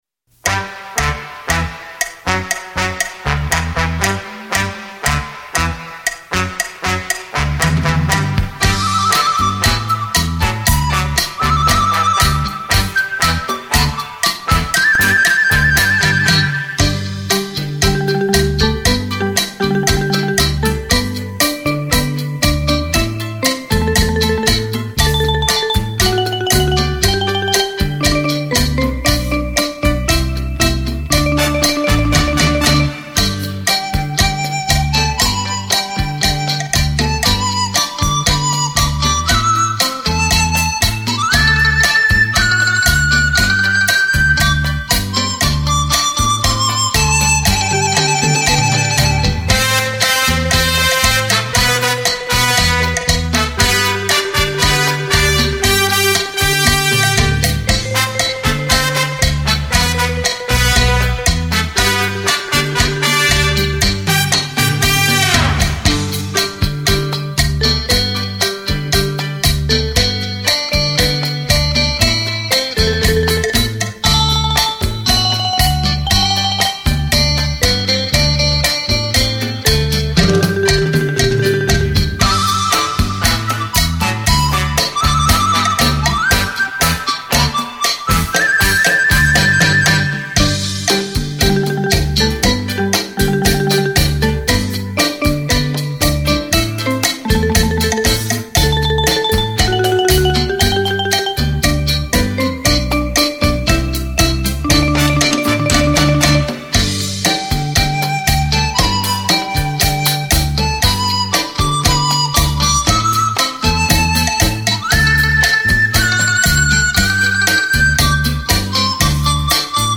属性：音乐、舞曲CD、标准型光盘、散曲合辑
专辑格式：DTS-CD-5.1声道
按照舞厅的标准现场，标准速度来演奏的舞曲系列之一。
30人大乐团环场演奏，是舞林朋友的华丽舞伴。